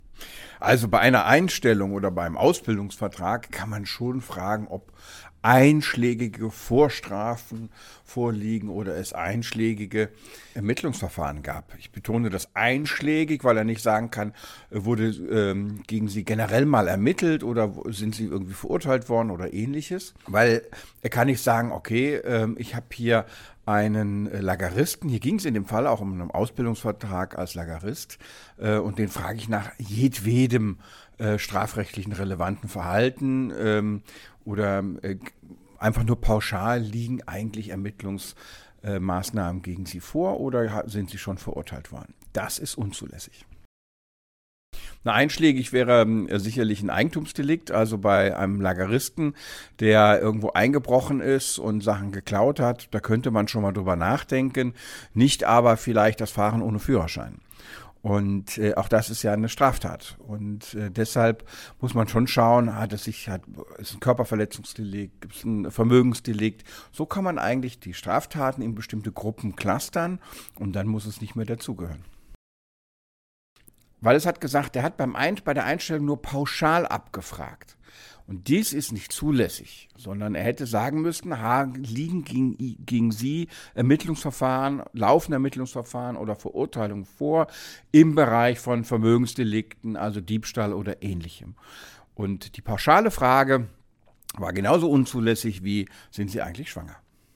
Kollegengespräch: Straftat verschwiegen – Ausbildungsvertrag bleibt